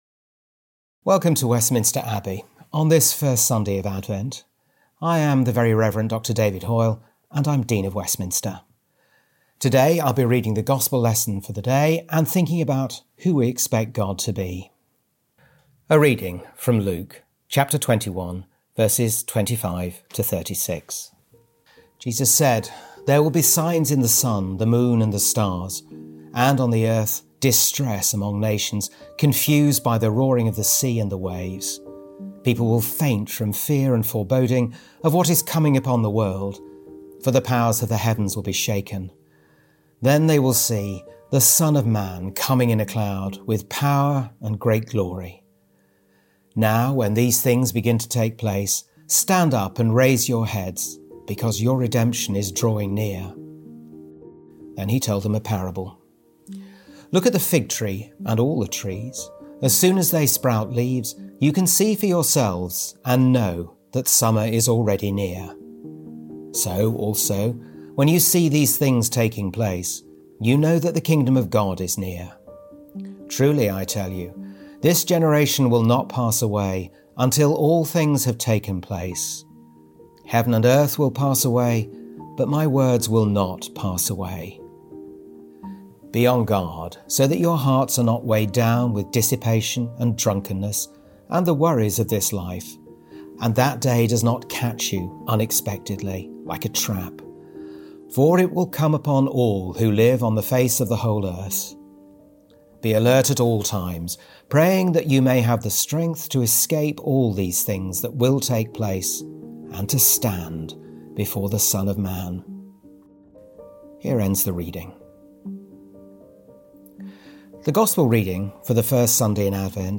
Listen as The Very Reverend Dr David Hoyle, Dean of Westminster, reflects on Luke’s gospel passage as we begin Advent and the new church year.